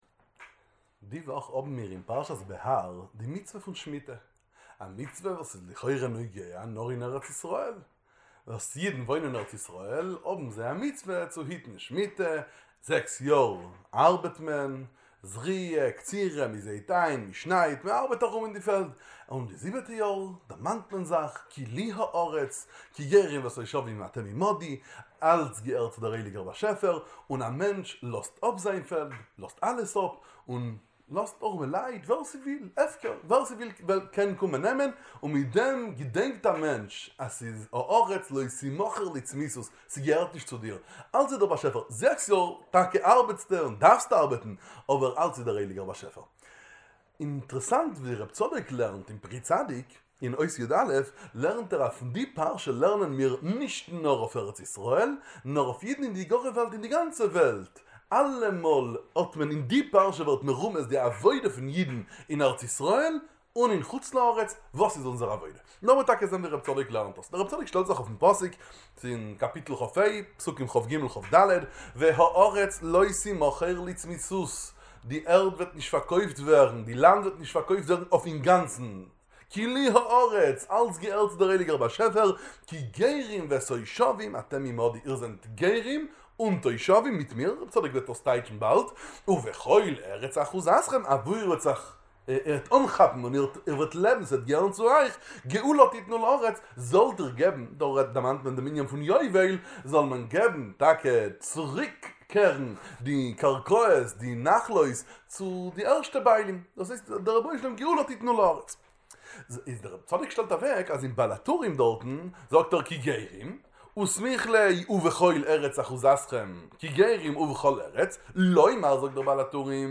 שיעורים בספר פרי צדיק באידיש